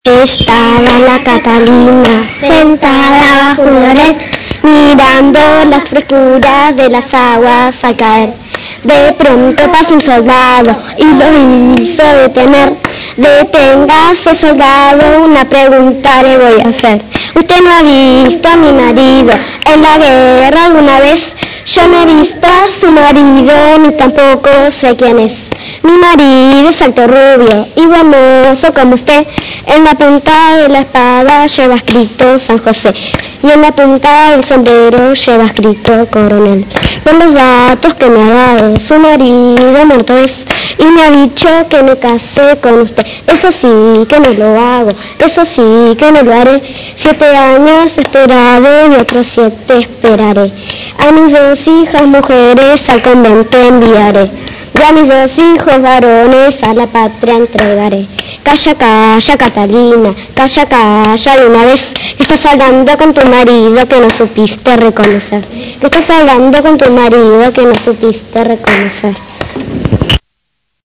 Escuchar juegos con palmas
ESTABA LA CATALINA (Voces infantiles 3)
juegos_con_palmas_3.mp3